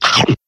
chomp 1 sound effects
chomp-1